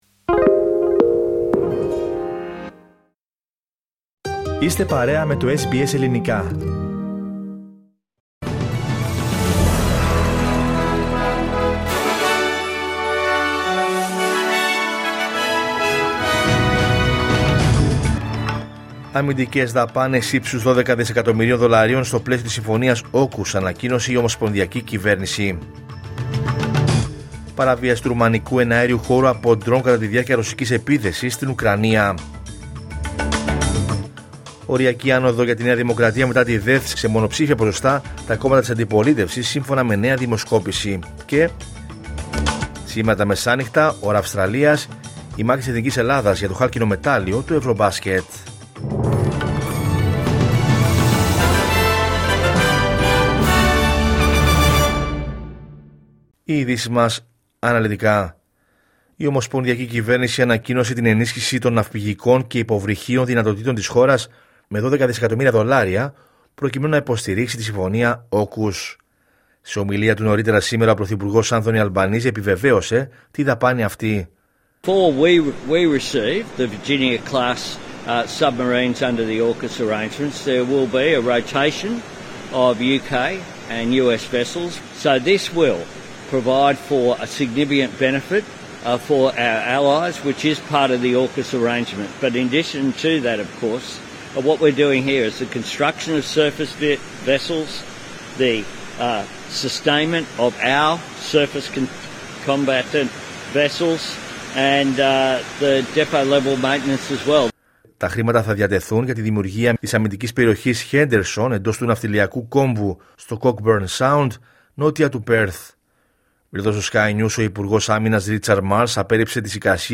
Ειδήσεις: Κυριακή 14 Σεπτεμβρίου 2025